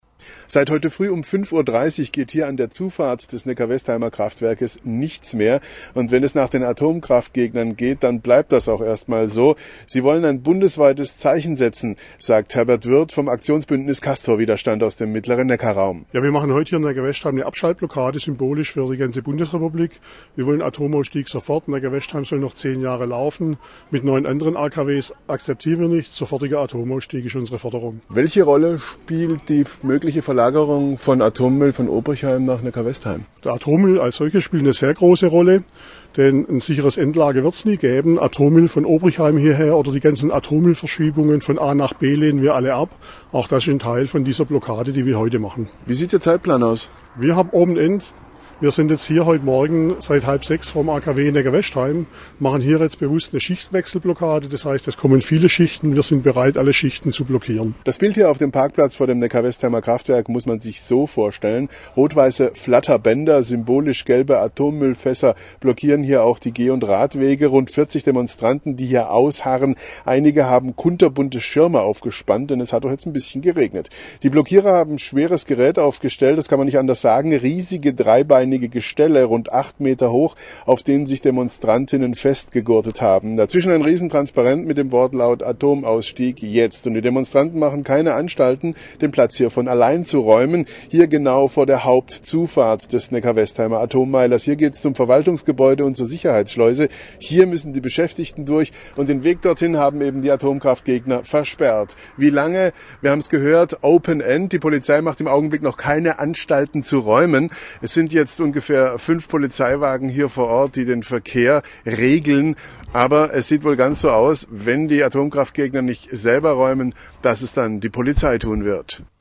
SWR Nachrichten: